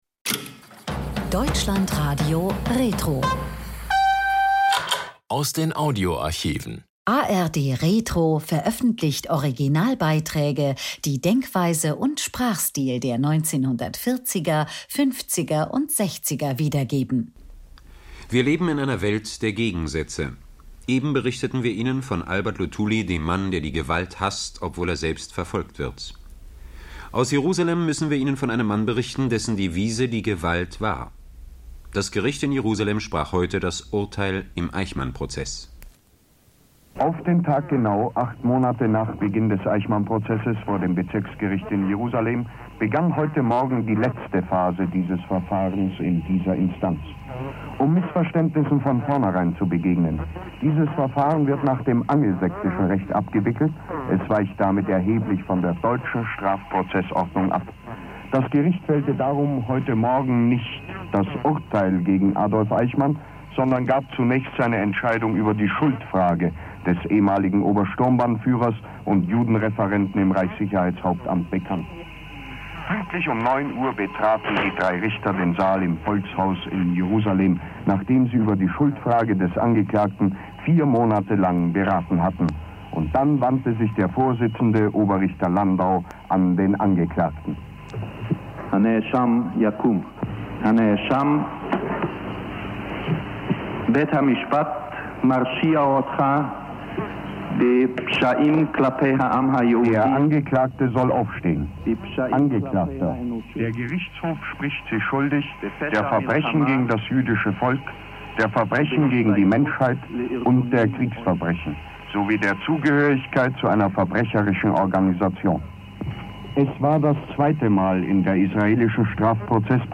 Zwei RIAS-Beiträge aus Jerusalem: Am 11.12.1961 wird Adolf Eichmann in allen Punkten schuldig gesprochen, am 15.12.1961 das Todesurteil verkündet.